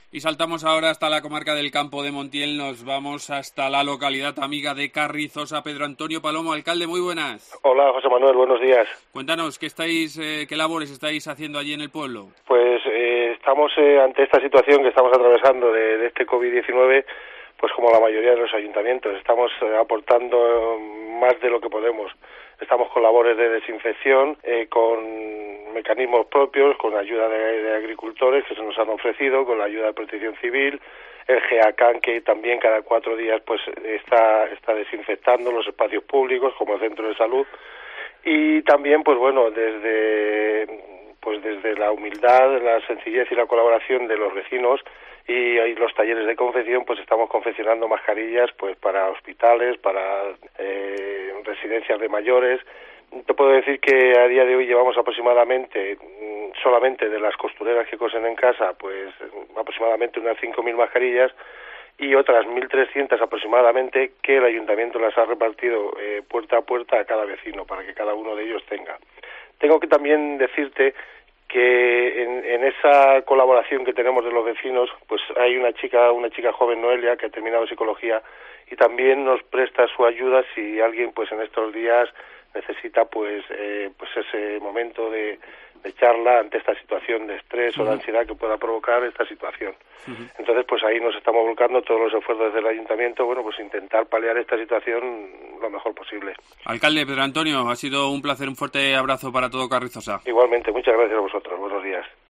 Pedro Antonio Palomo, alcalde de Carrizosa